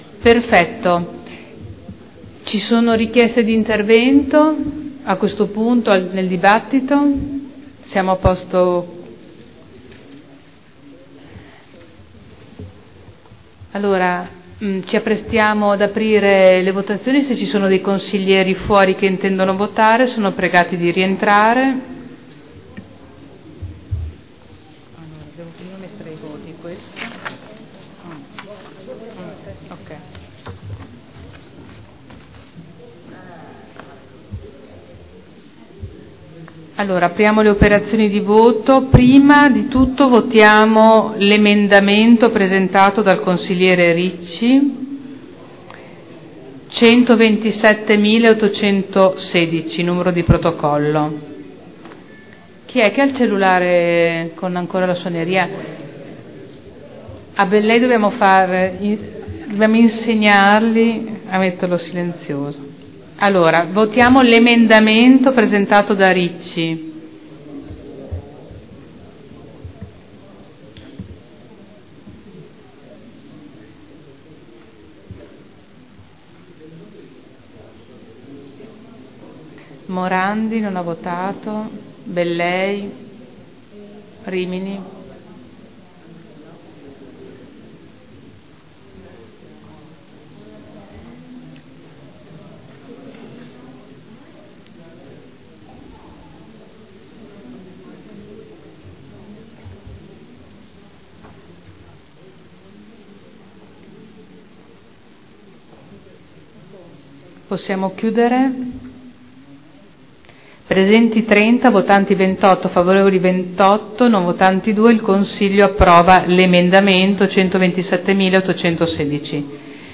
Seduta del 29/10/2012 Il presidente Caterina Liotti mette ai voti l'emendamento Ricci n. 127816: approvato. Mette ai voti l'emendamento Barcaiuolo n. 127899 approvato.